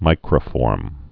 (mīkrə-fôrm)